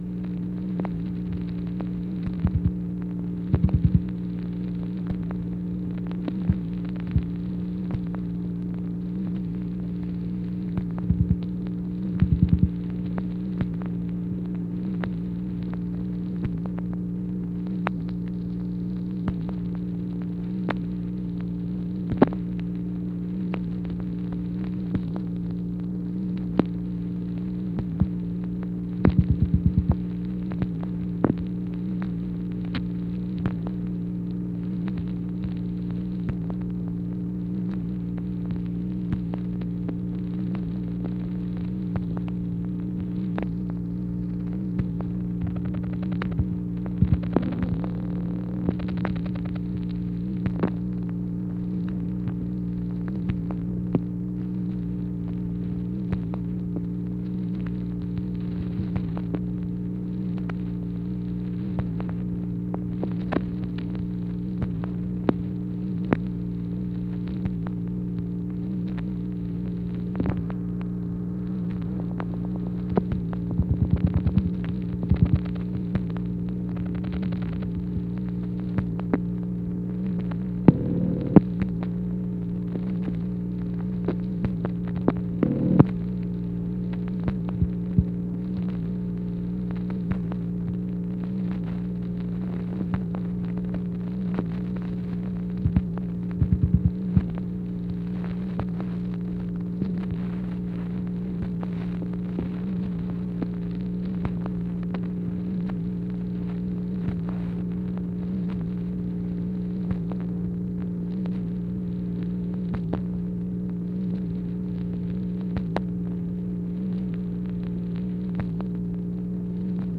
MACHINE NOISE, February 5, 1964
Secret White House Tapes | Lyndon B. Johnson Presidency